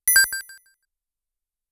チェック音